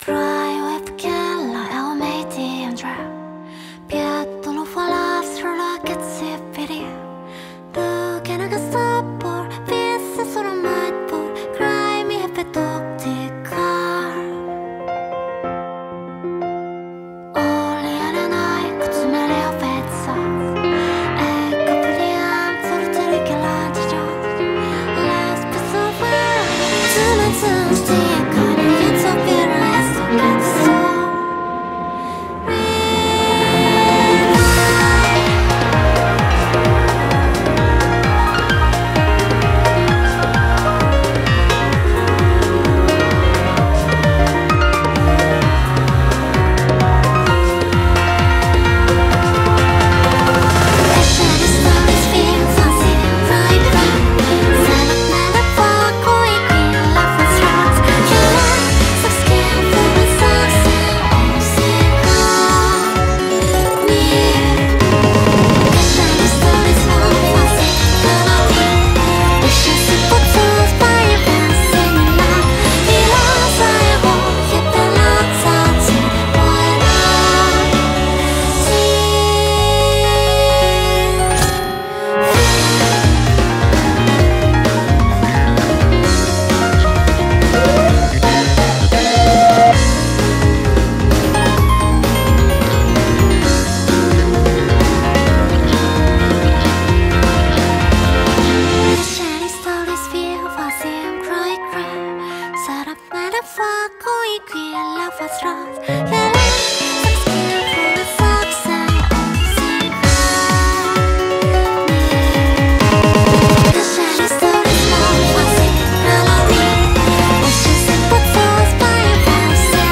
BPM125-130
Audio QualityPerfect (High Quality)